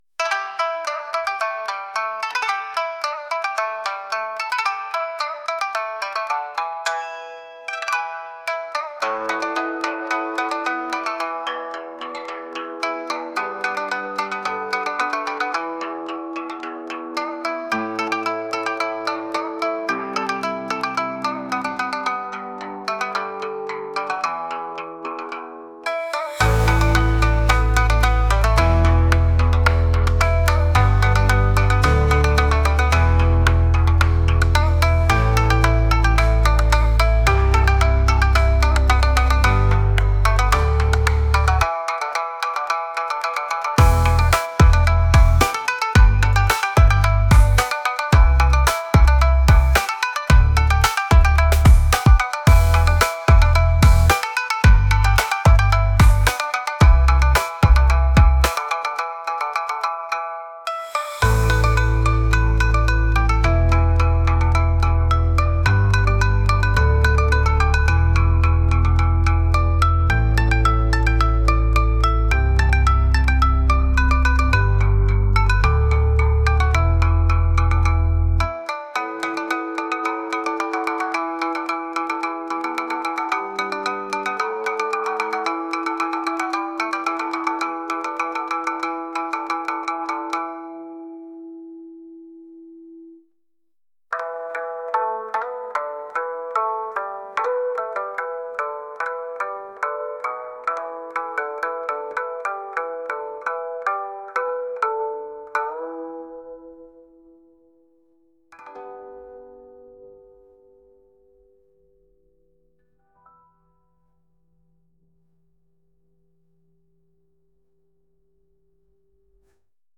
Japanese Chill Track